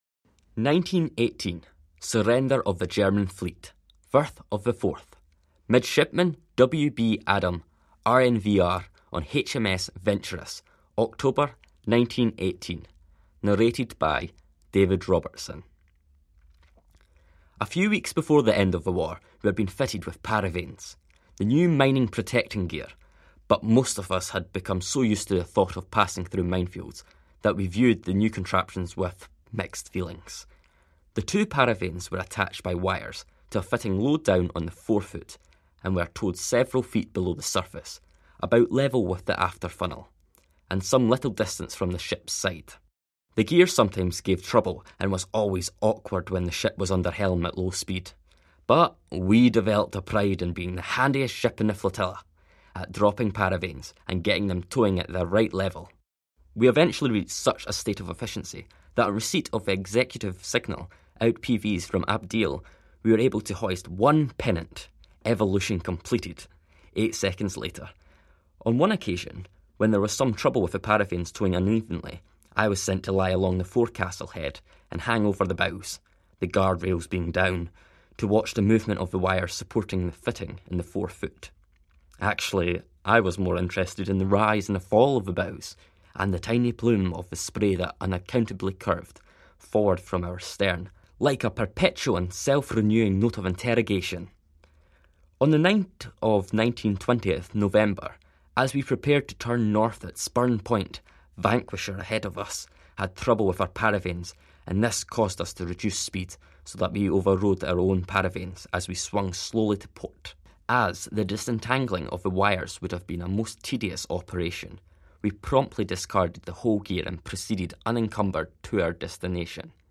Here is a recording of the final chapter of his diary, covering the Surrender of the German High Seas Fleet in the Forth, November 2018.